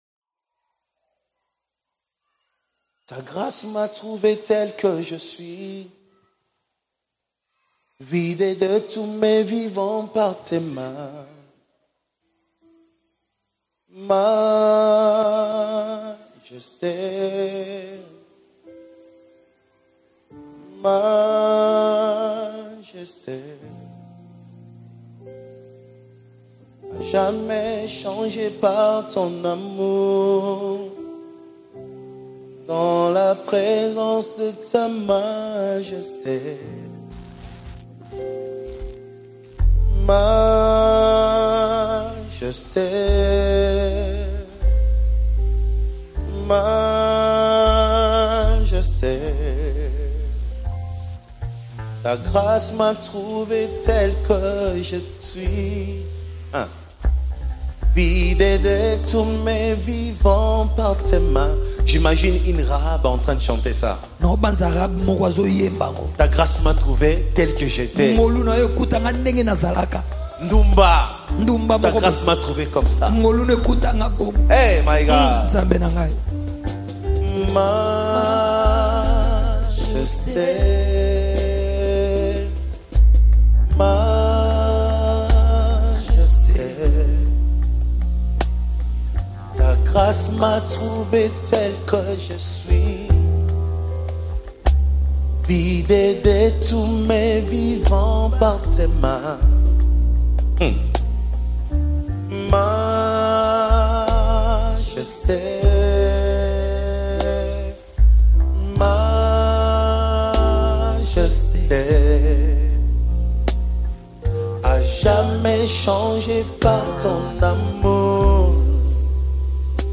ANOINTED WORSHIP ANTHEM